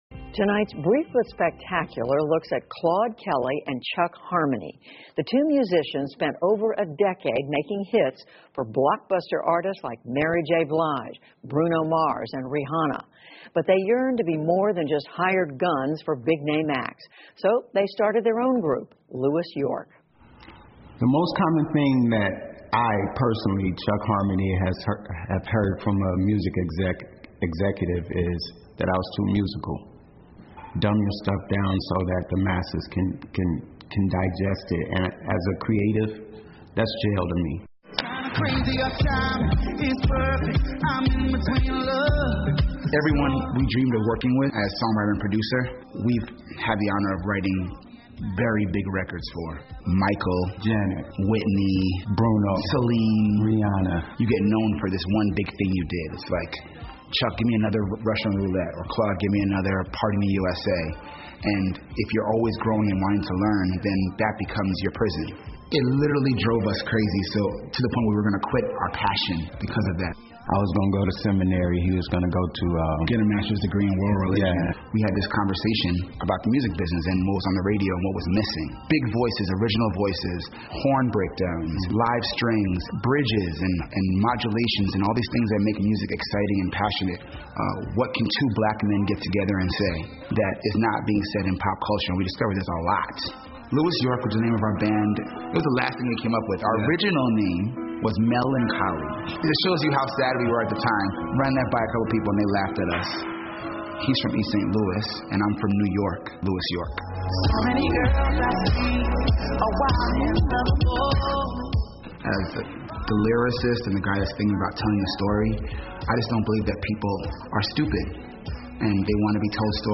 PBS高端访谈:创造许多经典名曲的"路易纽约" 听力文件下载—在线英语听力室